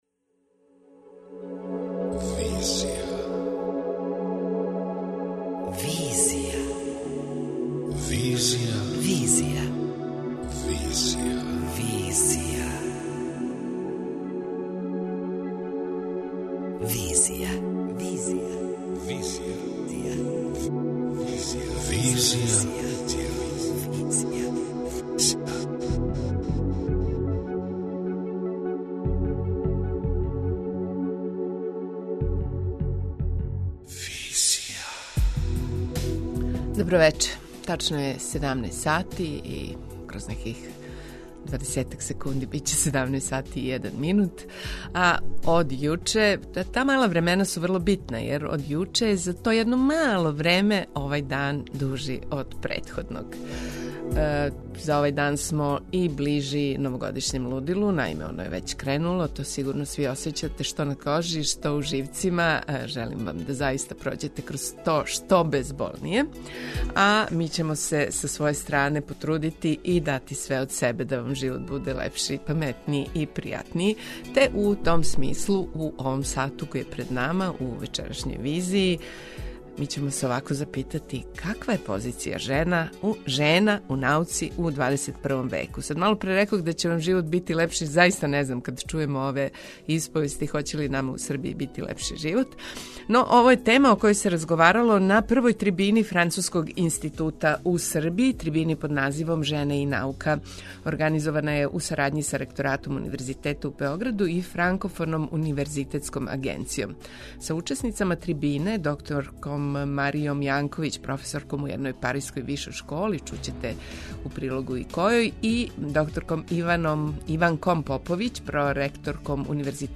преузми : 27.14 MB Визија Autor: Београд 202 Социо-културолошки магазин, који прати савремене друштвене феномене.